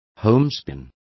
Complete with pronunciation of the translation of homespun.